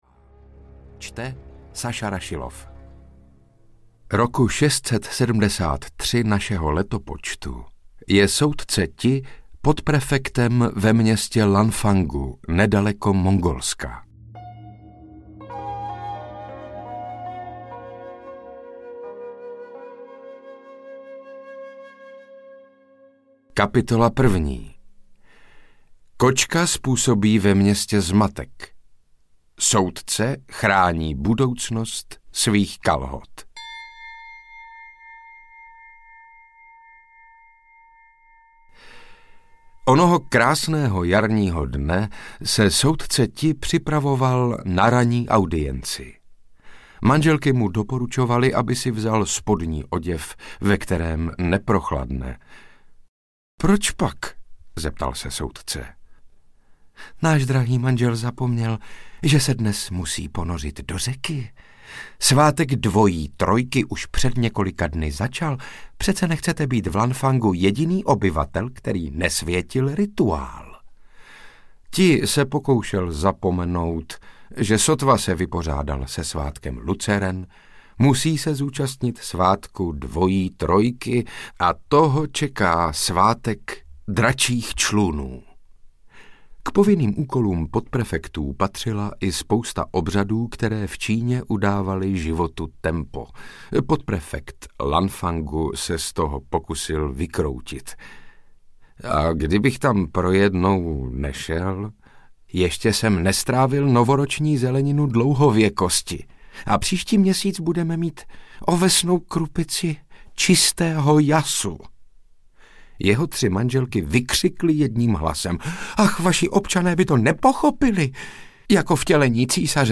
Případy soudce Ti: Noc kočky audiokniha
Ukázka z knihy
• InterpretSaša Rašilov